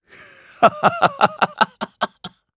Lusikiel-Vox_Happy3_jp.wav